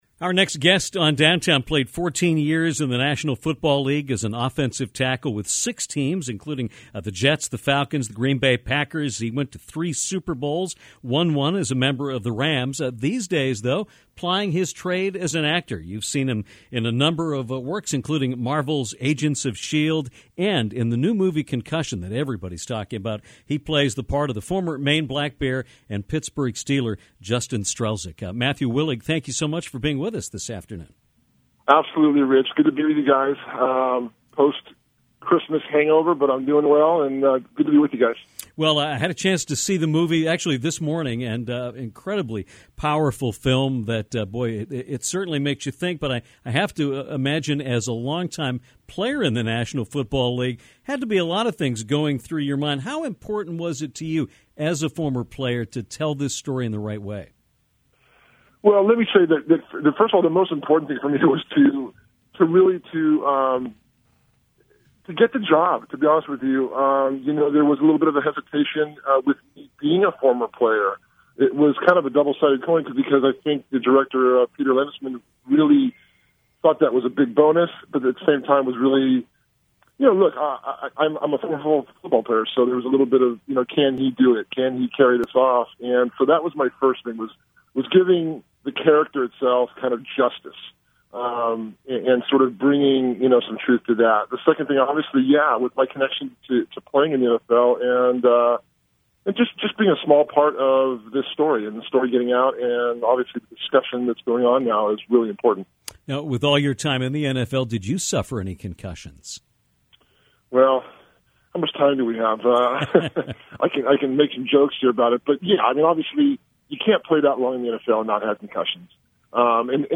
Actor and former football player Matthew Willig joined Downtown to talk about playing former Maine Black Bear and Pittsburgh Steeler Justin Strzelczyk in the movie “Concussion”.